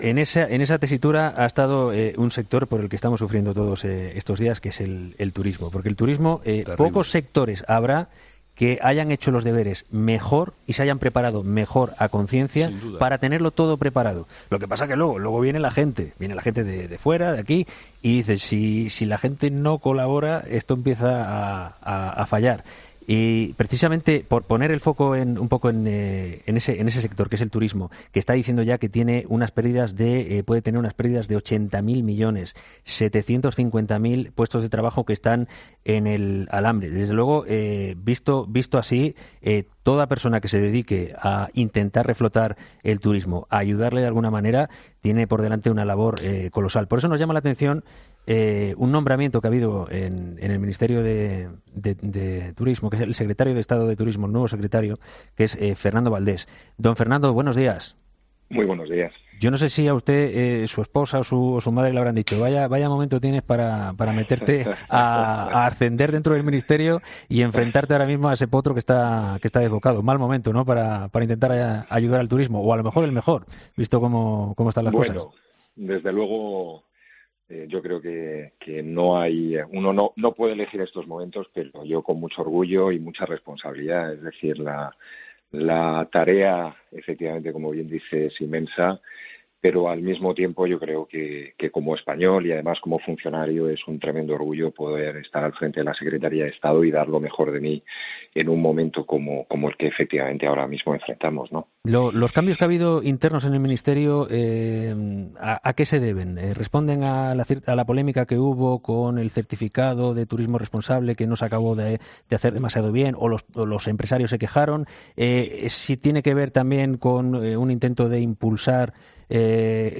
Fernando Valdés, que recientemente ha sido designado como Secretario de Estado de Turismo, ha comparecido esta mañana en 'Herrera en COPE', donde...